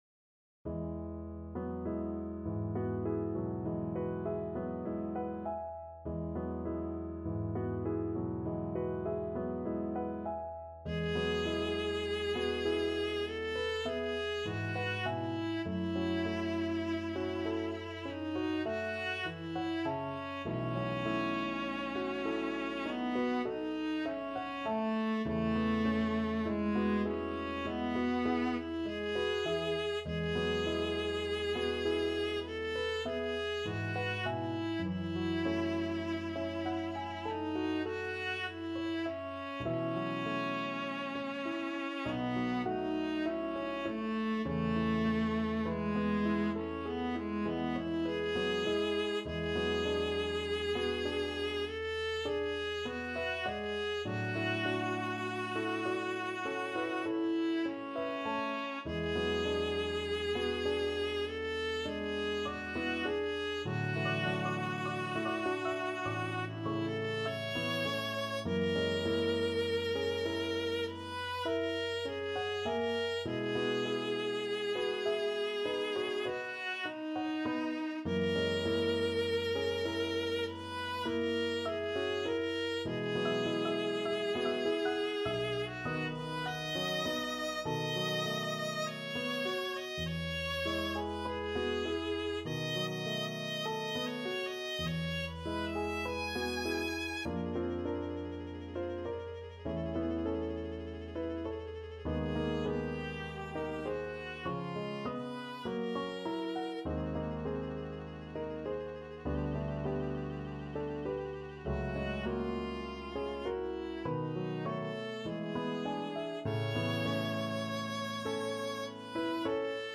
Viola version
4/4 (View more 4/4 Music)
Lento =50
Classical (View more Classical Viola Music)